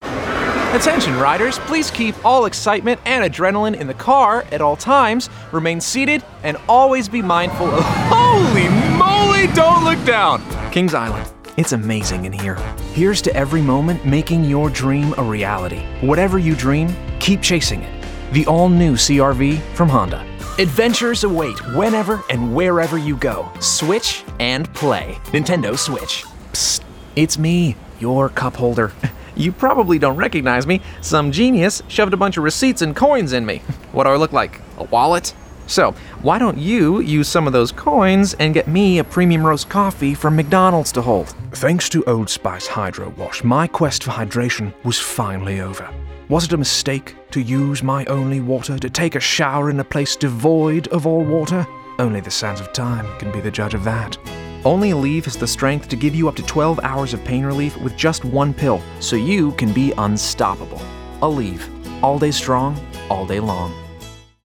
Voiceover : Commercial : Men